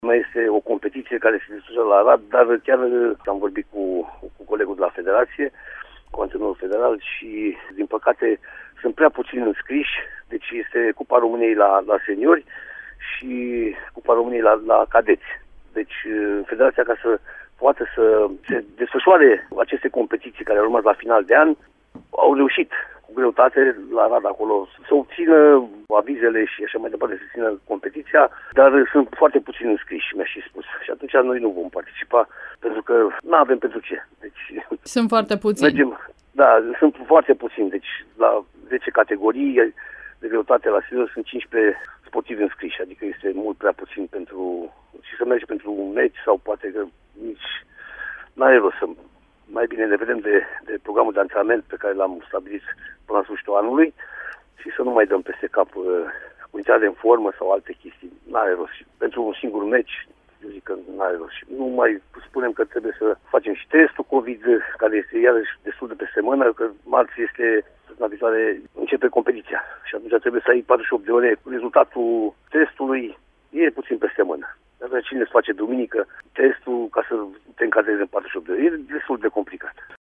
Fostul campion mondial, Francisc Vaștag, explică de ce sportivii pe care îi antrenează la CSM Reșița nu vor participa la această competiție: